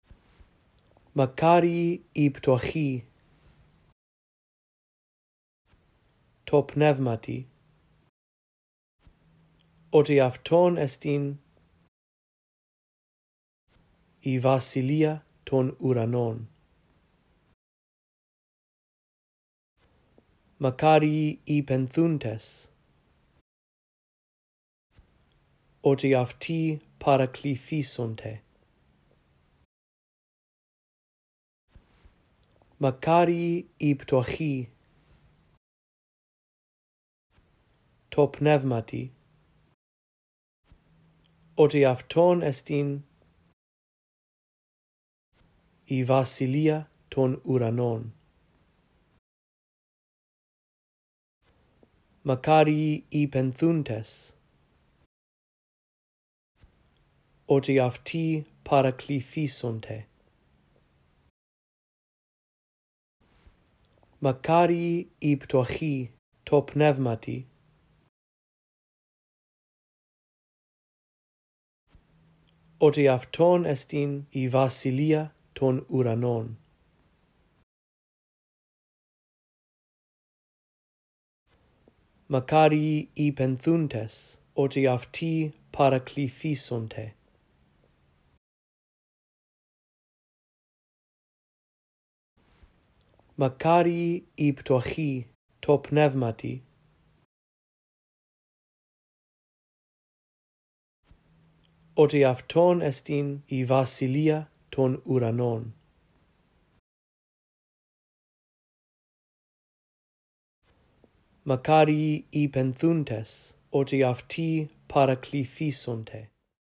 In this audio track, I read through verses 3-4 a phrase at a time, giving you time to repeat after me. After two run-throughs, the phrases that you are to repeat become longer.